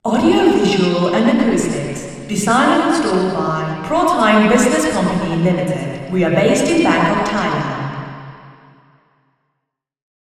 Not surprisingly, this placement produces some audible flutter echoes, but the improvement in SI is striking.
Receiver 03     STI = 0.53
RIR_MFA_W_A1_03_Female_Anechoic_Speech_MONO.wav